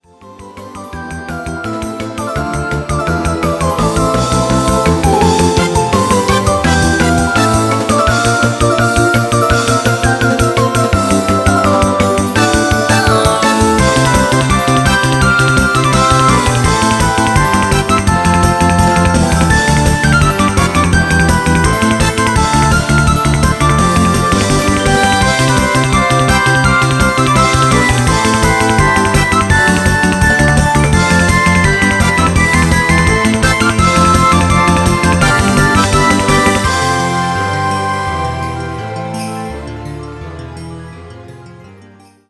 ポップでカラフルな音楽CDの第2弾！
07 [twinkle pop]